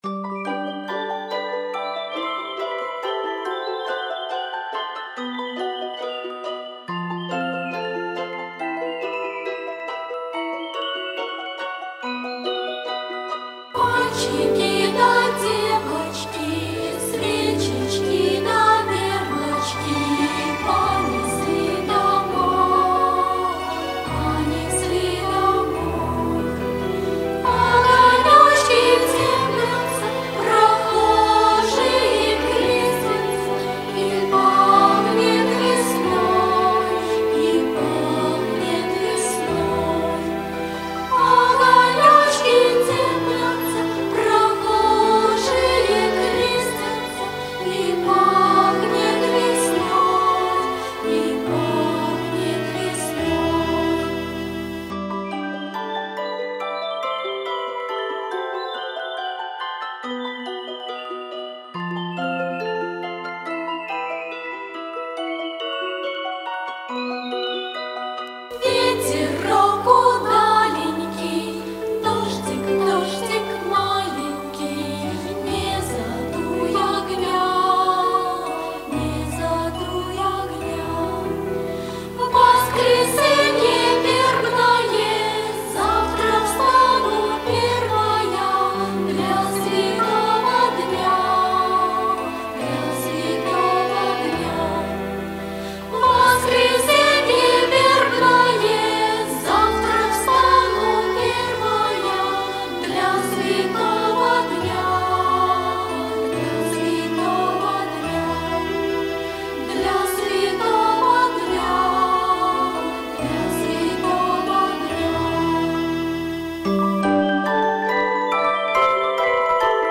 Easter songs performed by children are characterized by a special emotionality.